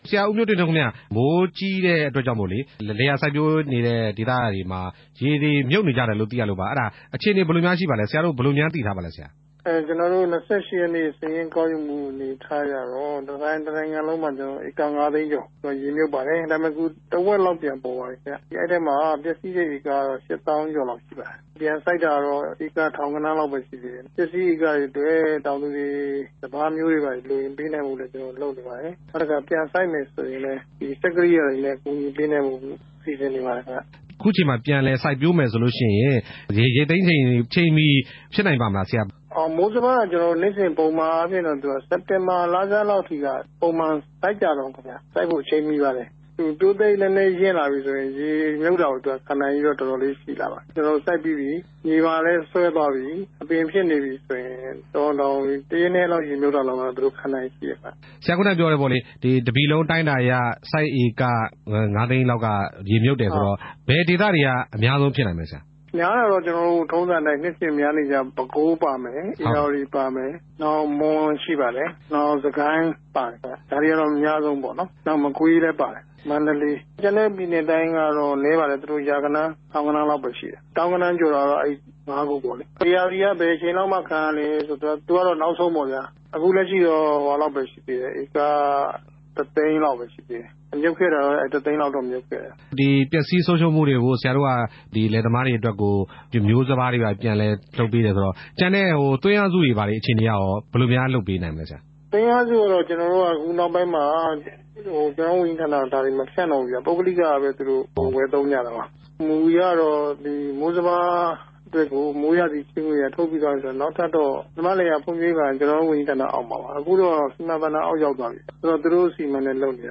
ပျက်စီးသွားတဲ့လယ်တွေအတွက် မျိုးစပါးနဲ့ စိုက်ပျိုးဖို့ စက်ကိရိယာတွေ ကူညီပေးနေတယ်လို့ စိုက် ပျိုးရေး၊ မွေးမြူရေးနဲ့ ဆည်မြောင်းဝန်ကြီးဌာန အမြဲတန်းအတွင်းဝန် ဦးမျိုးတင့်ထွန်းက ပြောပါတယ်။